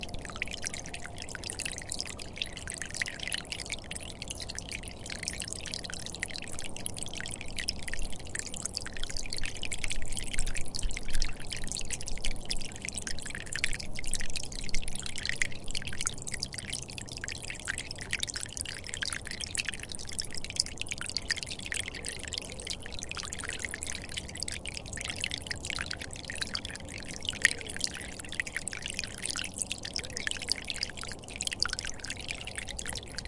各种各样的 " 冰盒
描述：冰块的声音关闭，用Zoom H4n录制。
Tag: 裂纹 立方体 冰块 冷冻